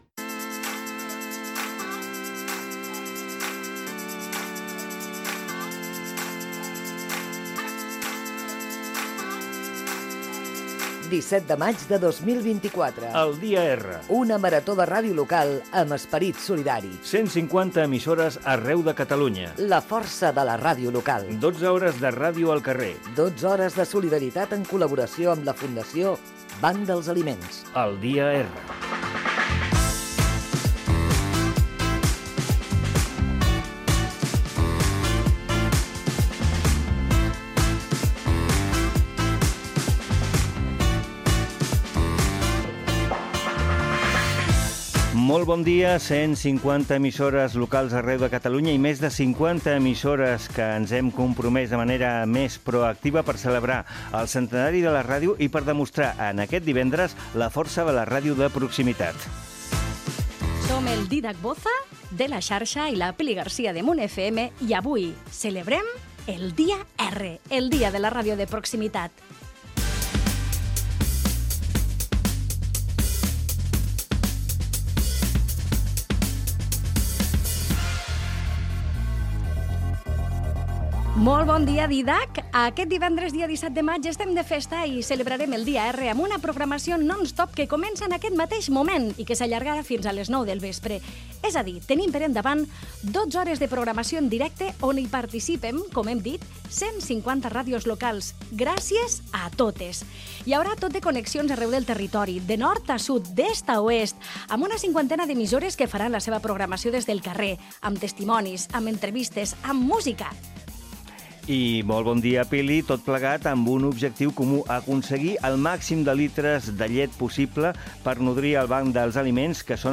Identificació del programa, presentació, objectiu del programa especial, fragment que es farà també per La Xarxa +. Connexió amb L'Espluga FM Ràdio. Ràdio Arenys farà el programa des de davant del Mercat